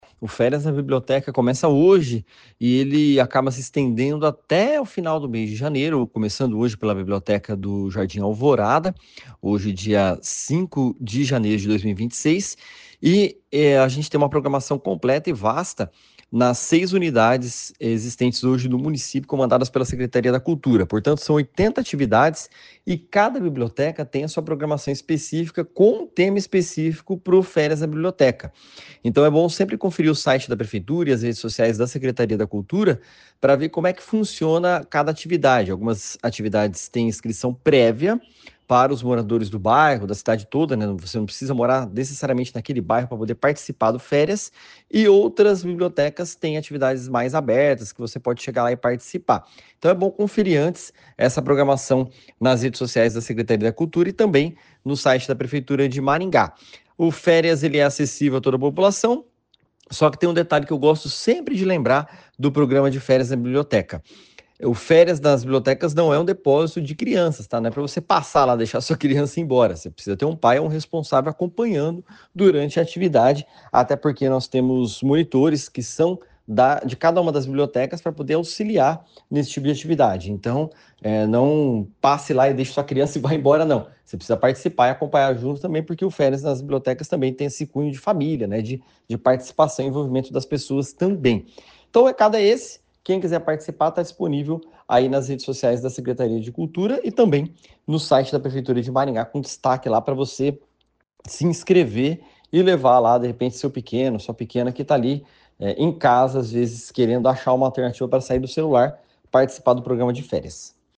Algumas atividades exigem inscrição prévia, como explica o secretário de Cultura, Tiago Valenciano.